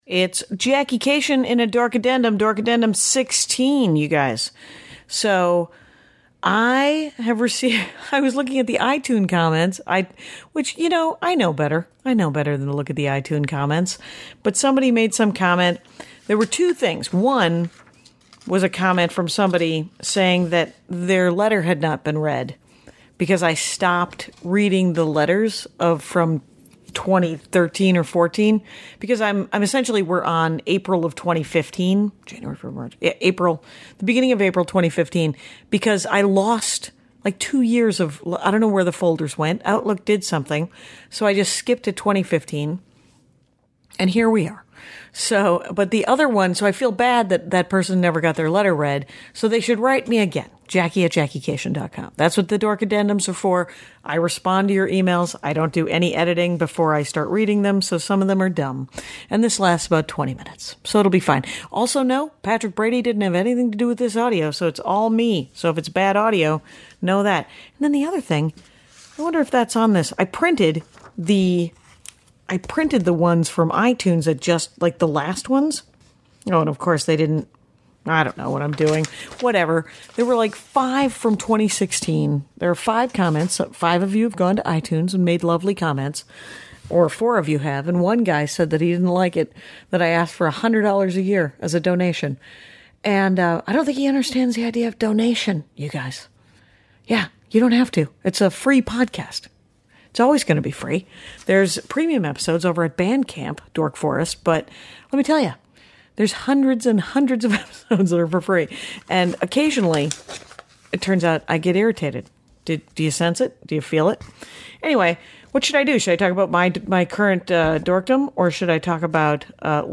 I read emails from April of 2015.